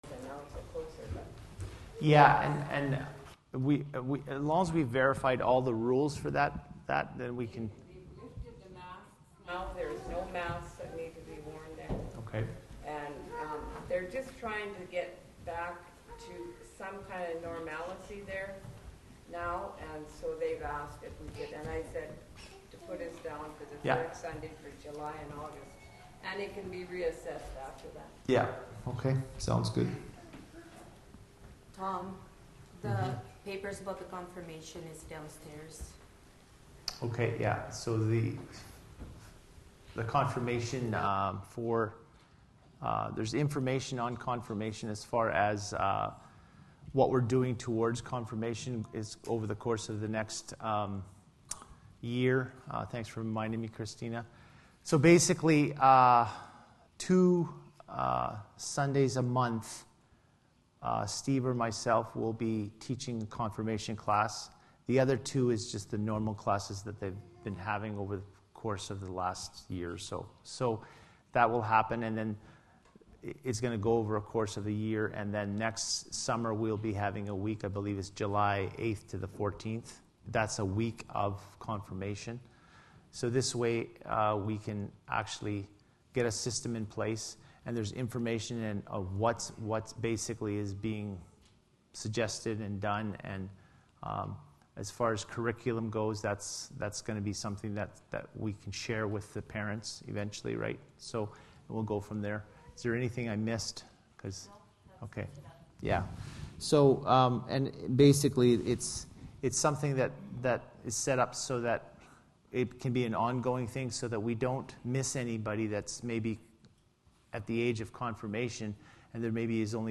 Sylvan Lake Apostolic Lutheran Church